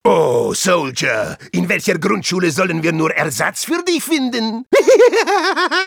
Voice file from Team Fortress 2 German version.
Spy_dominationsoldier04_de.wav